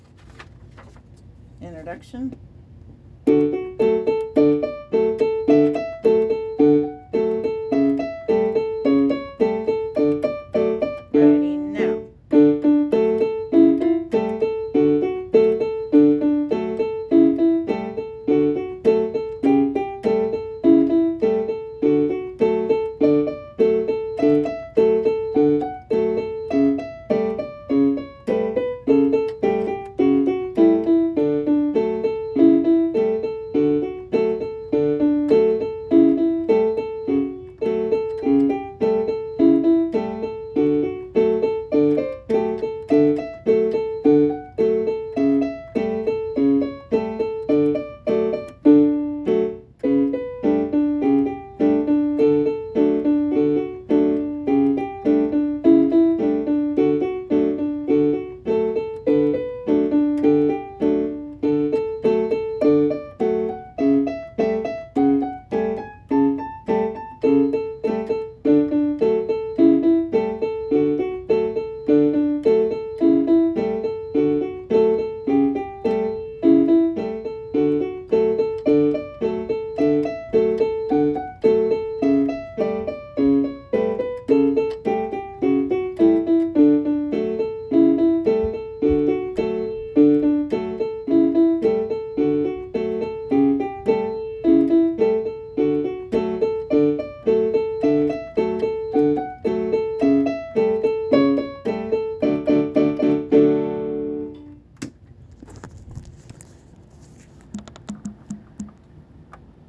Elementary Orchestra / Piano Parts: Play Along - 1st and 2nd Concerts Songs